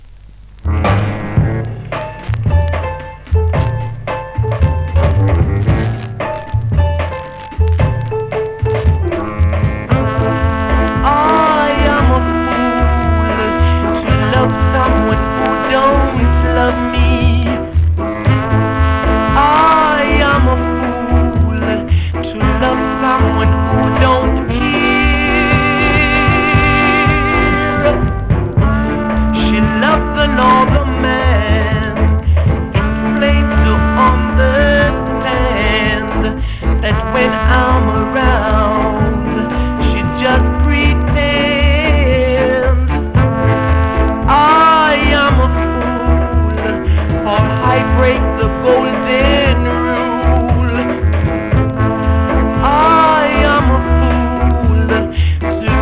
2026!! NEW IN!SKA〜REGGAE
※少し音割れ